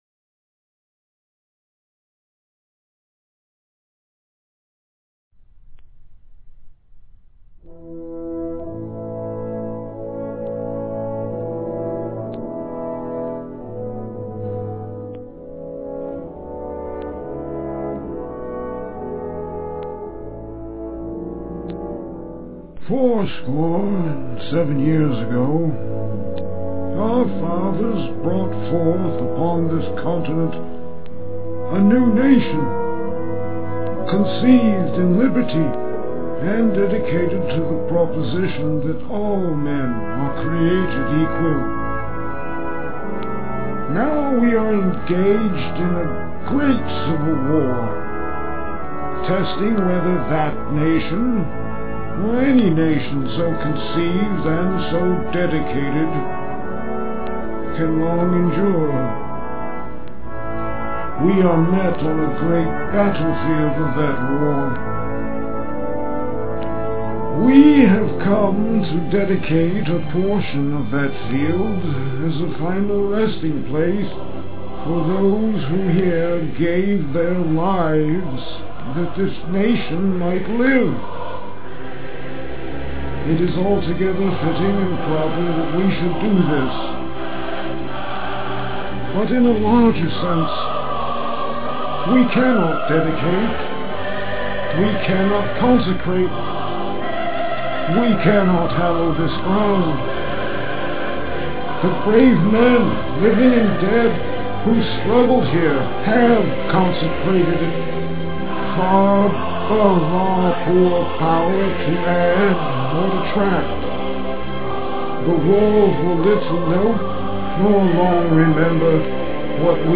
Being compressed, they sound "tinny" but the original MP3 files sound just fine and I've burnt them on to CD's to play in car stereos and such.
This recording has the background music of The Boston Pops and Choir, which I also used on the "Trilogy" recording below.